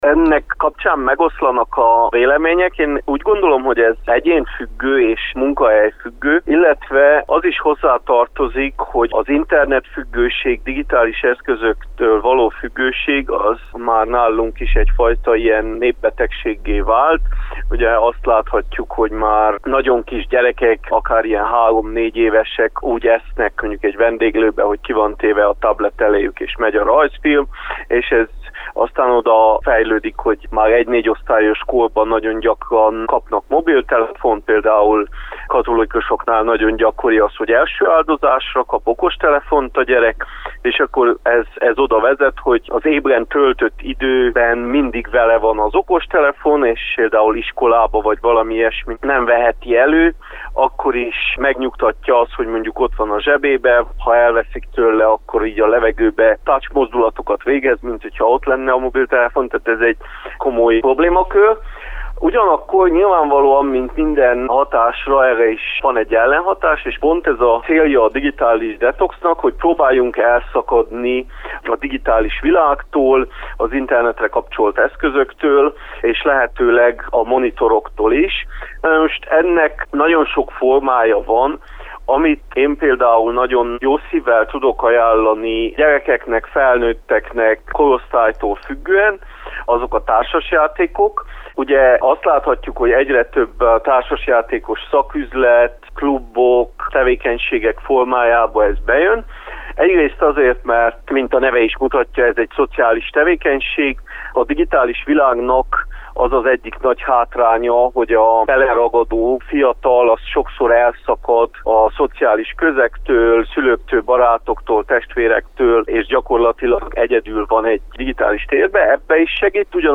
Informatikussal beszélgettünk a kütyühasználat csökkentésének hatásairól.